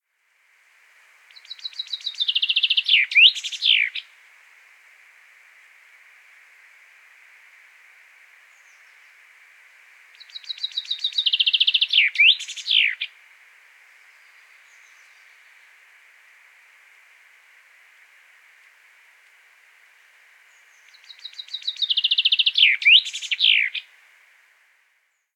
OGG hudba + A Chaffinch (Fringilla coelebs) singing in a spruce tree in Southern Finland. 19 May 2007 + pochází z Wikimedia Commons, kde má status – I, the copyright holder of this work, release this work into the public domain.
Fringilla_coelebs.ogg